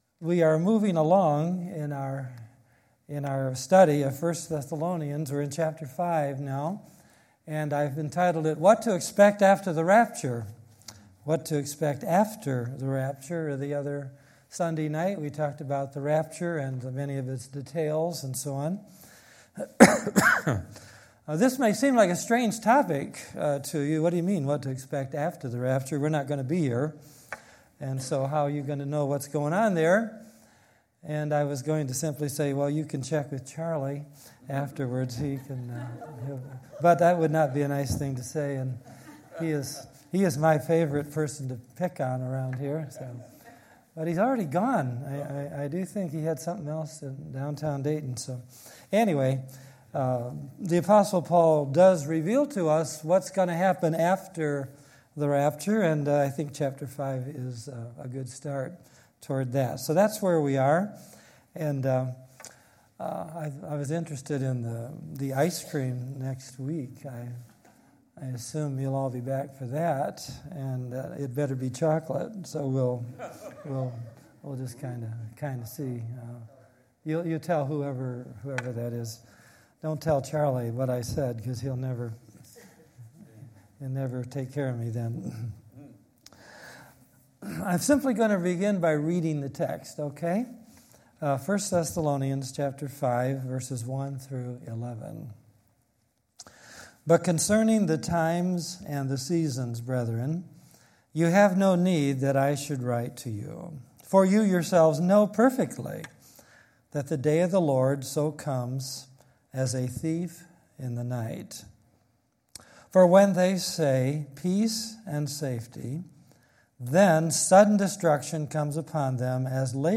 Sunday Evening Message